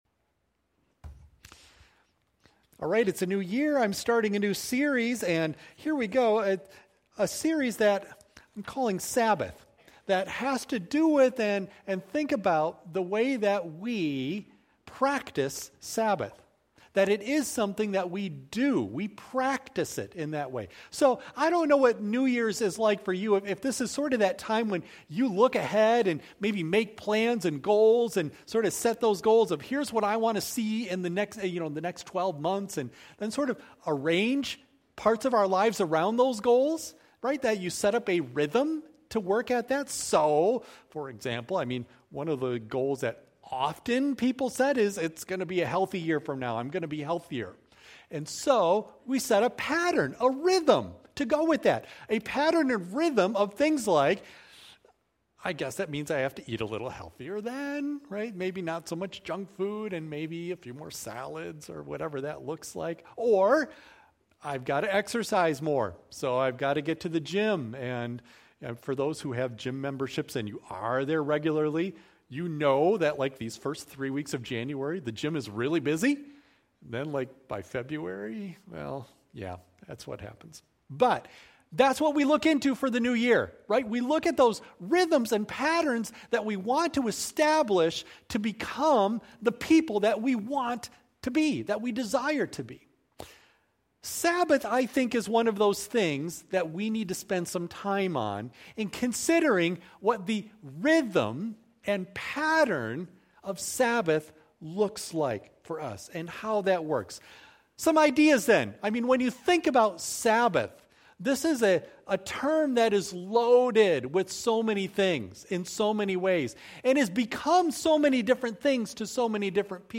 Worship Service
Audio of Message